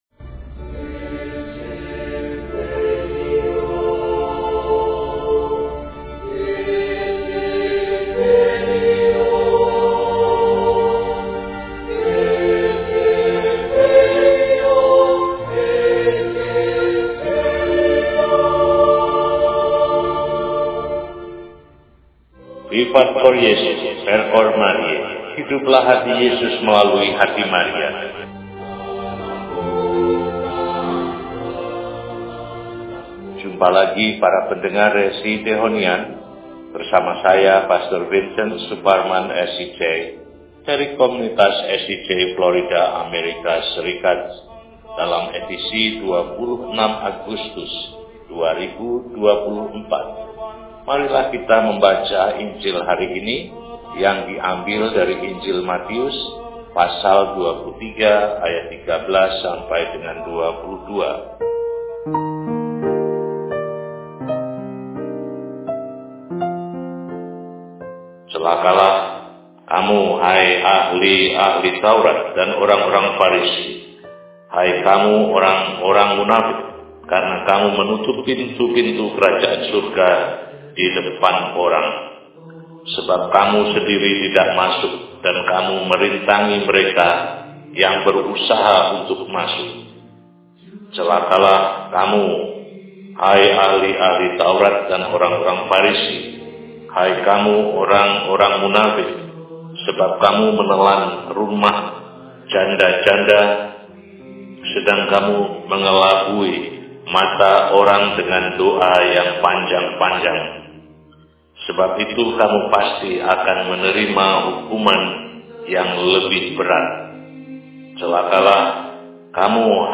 Senin, 26 Agustus 2024 – Hari Biasa Pekan XXI – RESI (Renungan Singkat) DEHONIAN